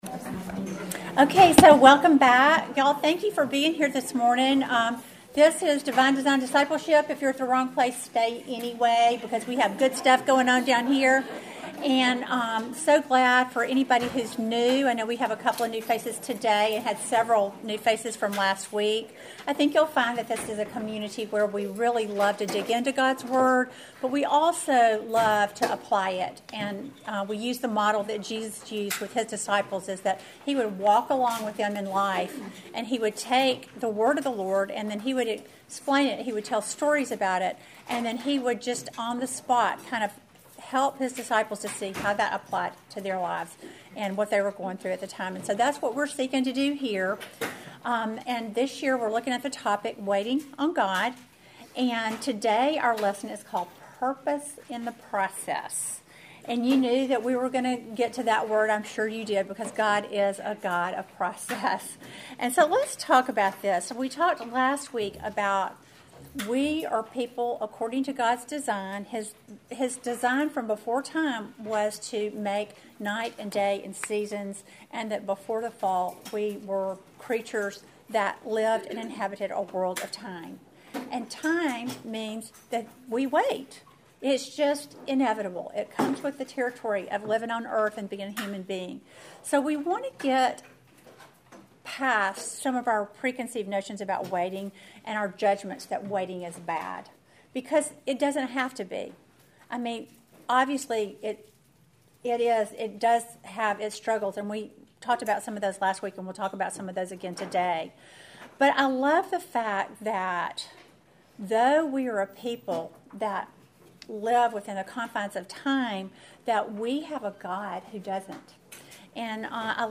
Welcome to the second lesson in our series WAITING ON GOD!